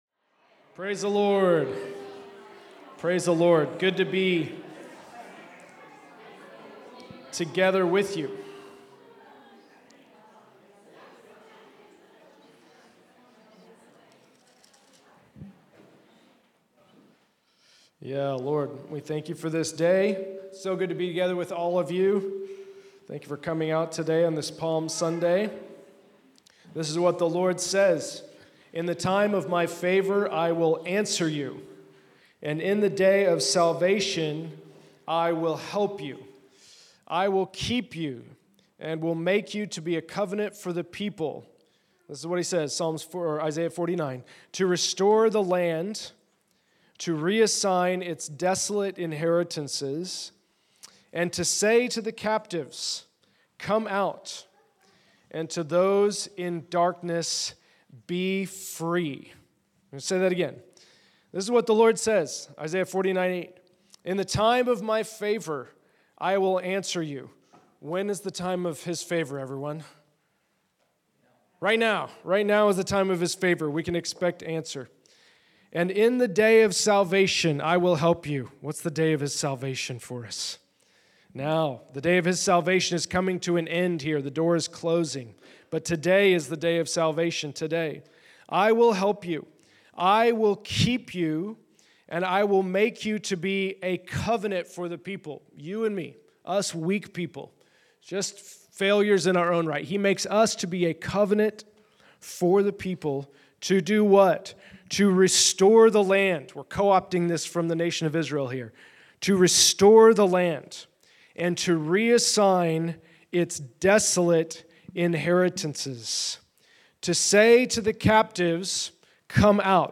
Location: El Dorado
We pray for freedom in the name of Jesus.